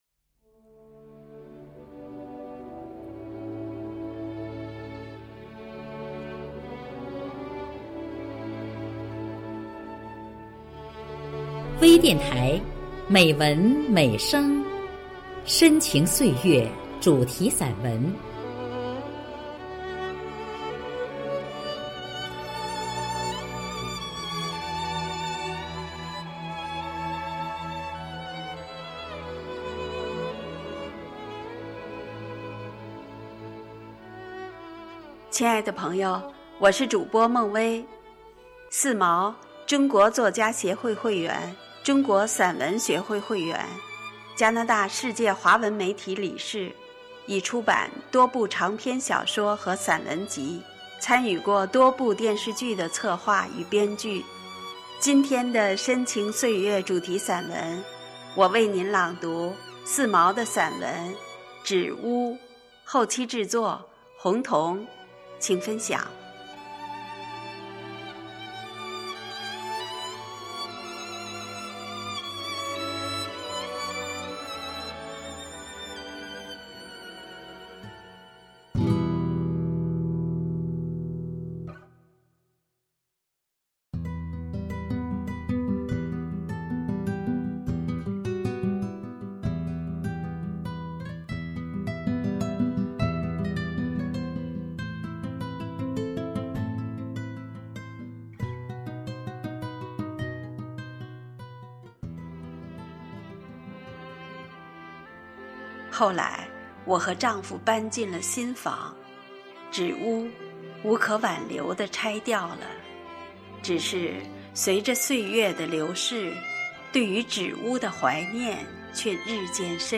专业诵读